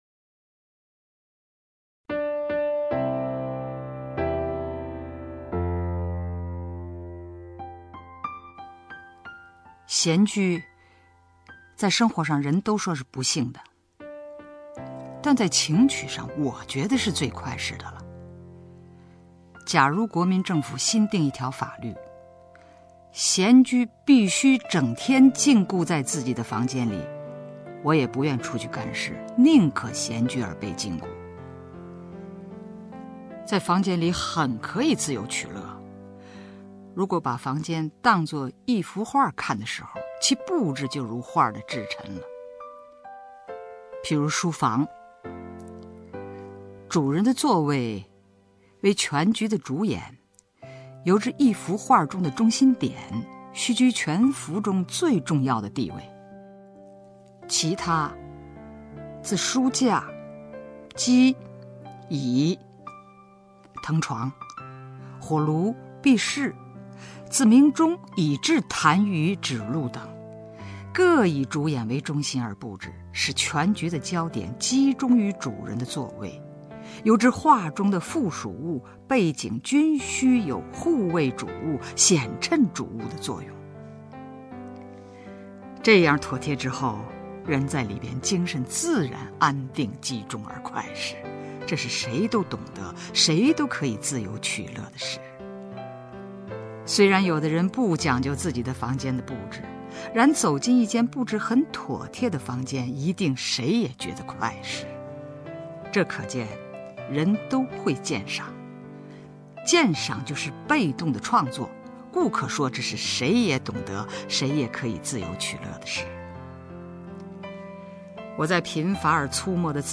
吕中朗诵：《闲居》(丰子恺)
名家朗诵欣赏 吕中 目录
XianJu_FengZiKai(LvZhong).mp3